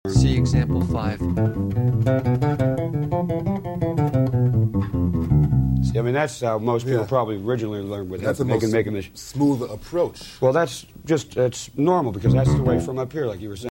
07 - Fretless Bass.mp3